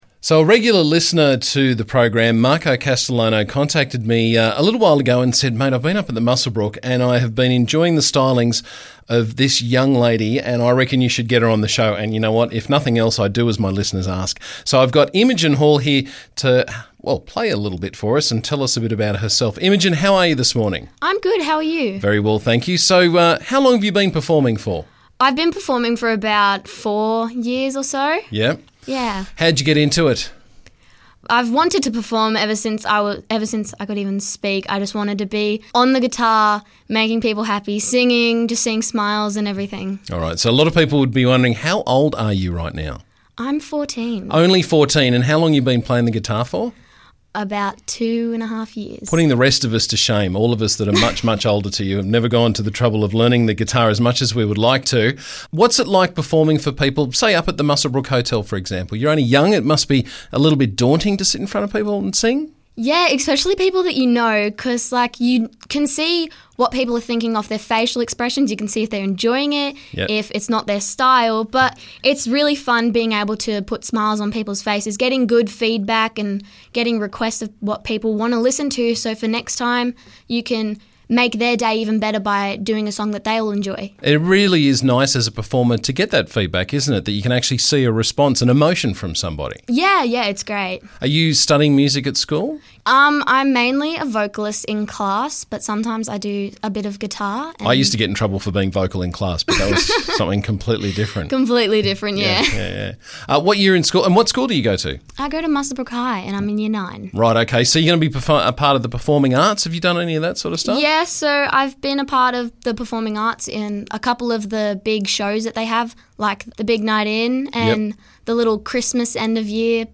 Local Singer
to tell us about herself and perform a little too.